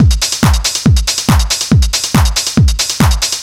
NRG 4 On The Floor 045.wav